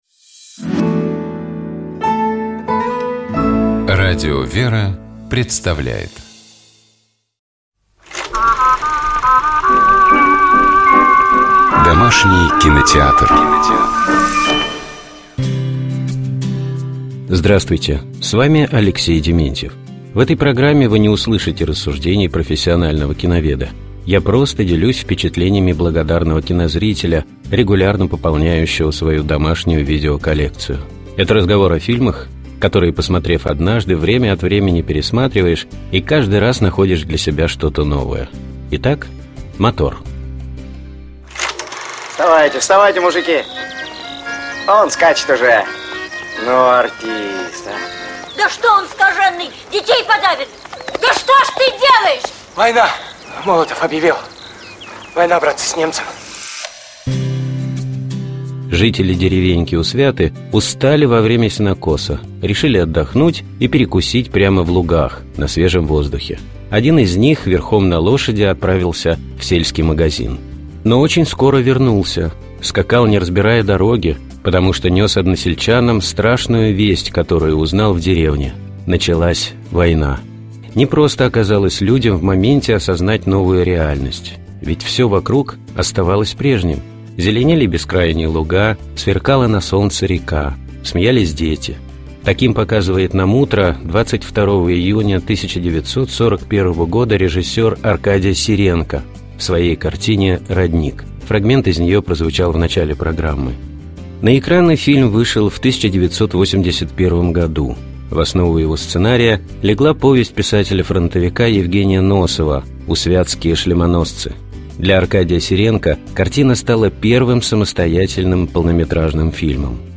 Таким показывает нам утро 22-го июня 1941-го года режиссёр Аркадий Сиренко в своей картине «Родник». Фрагмент из неё прозвучал в начале программы.